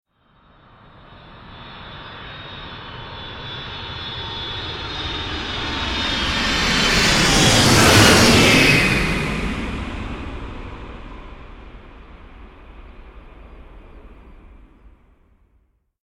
Pass By - Boeing 747